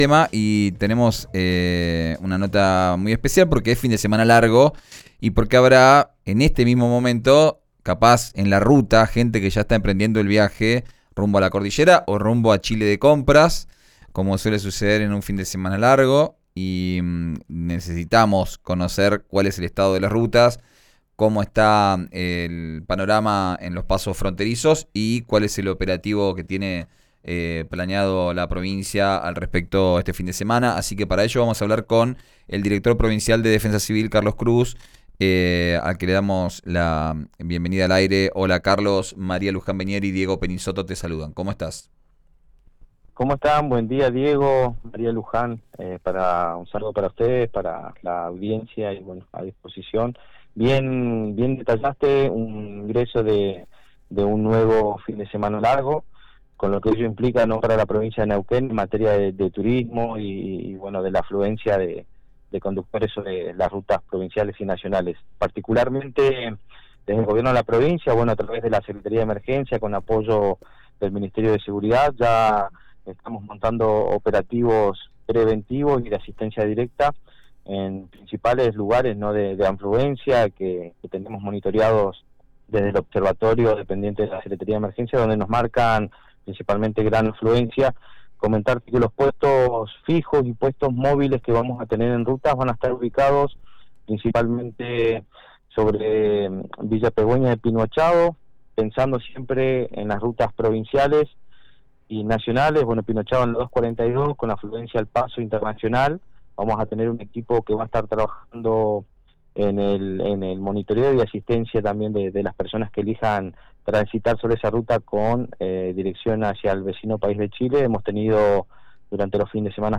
Escuchá a Carlos Cruz, director provincial de Defensa Civil, en RÍO NEGRO RADIO: